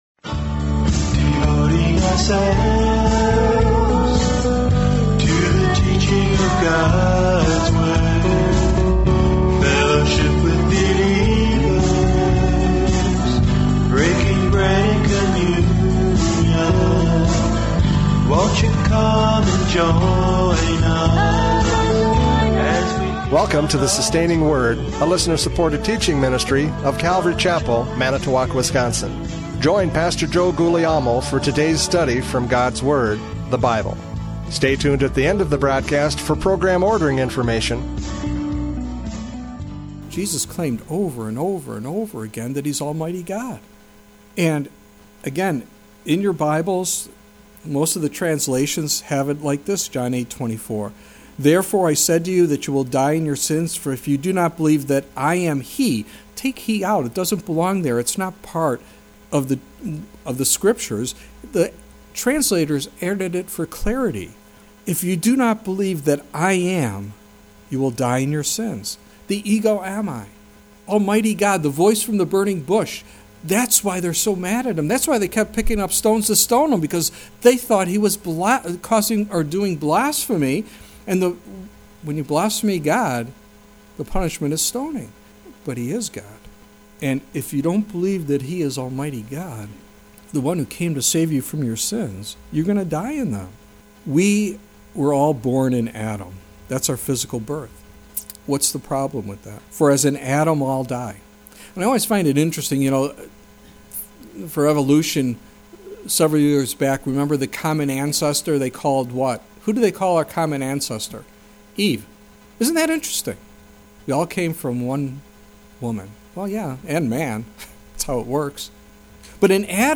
John 8:21-30 Service Type: Radio Programs « John 8:21-30 Life and Death!